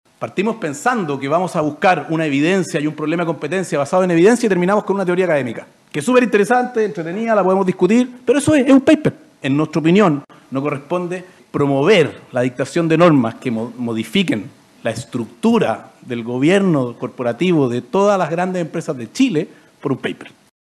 Se trató de una de las audiencias más esperadas del año, donde participaron abogados representantes de los grandes grupos económicos que operan en el país.